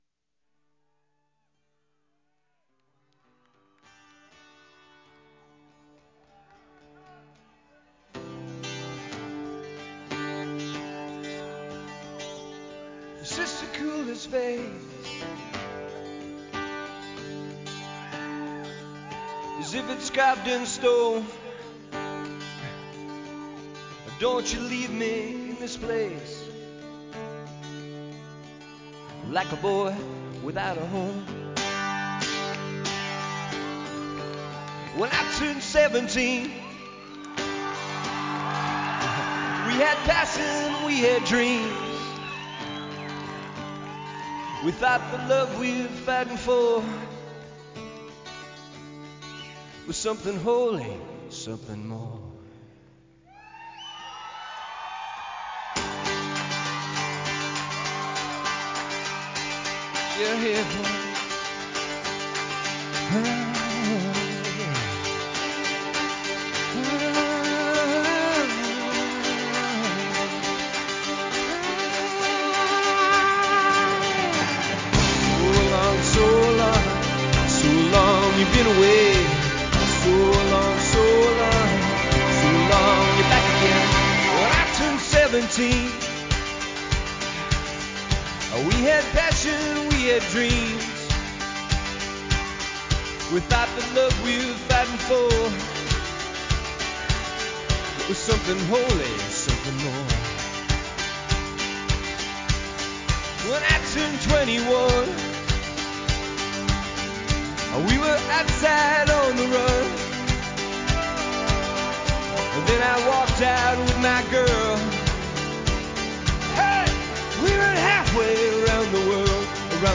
*Orchestral Version: